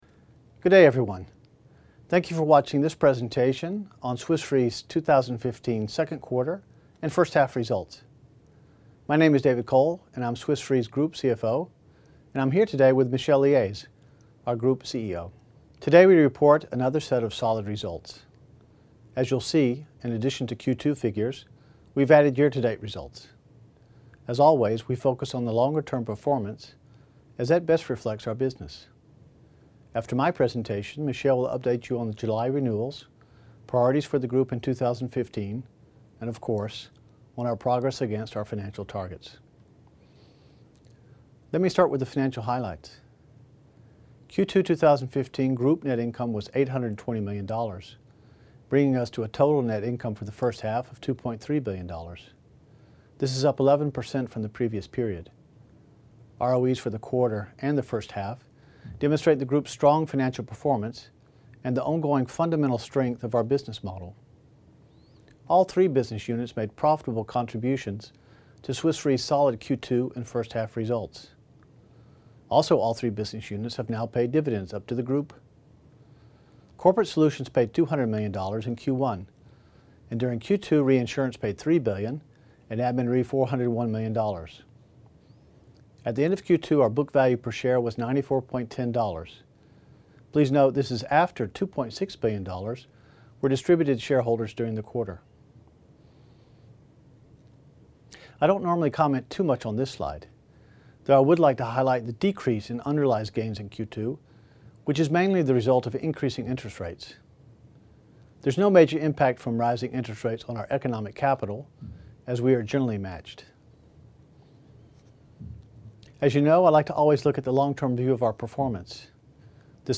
Audio of Q2 2015 Results Video Presentation